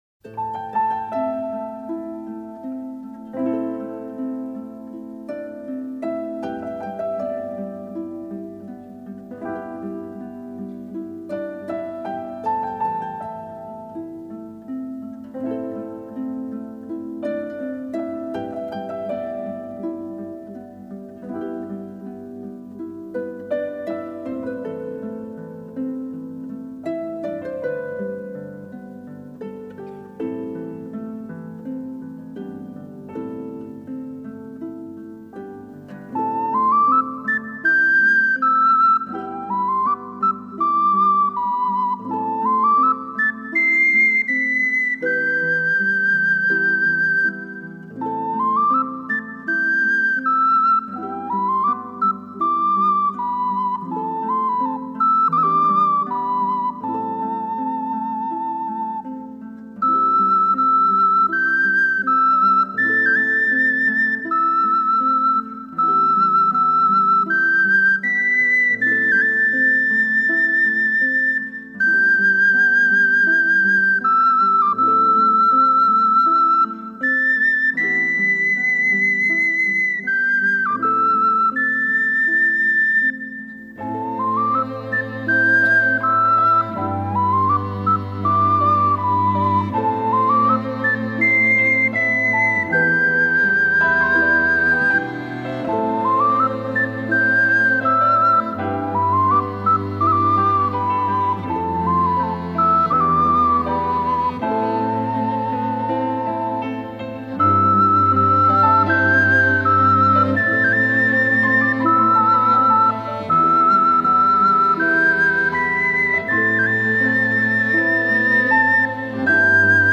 Genre: New Age.